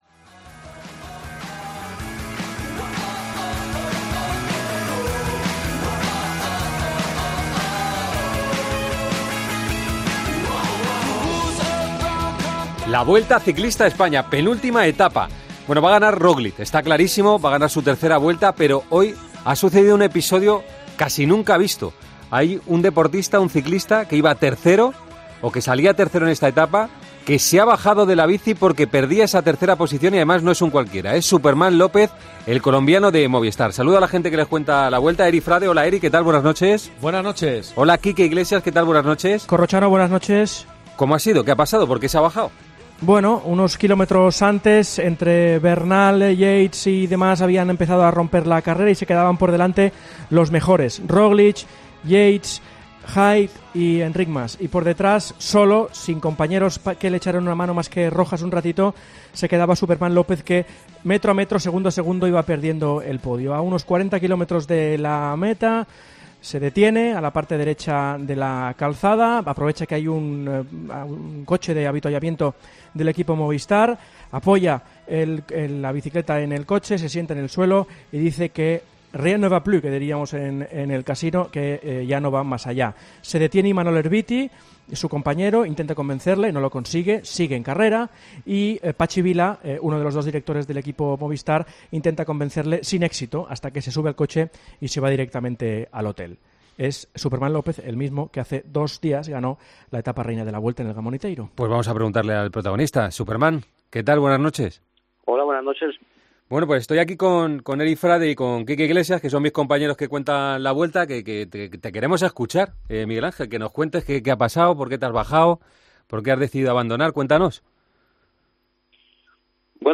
El colombiano ha dado explicaciones tras su abandono en una entrevista en Tiempo de Juego.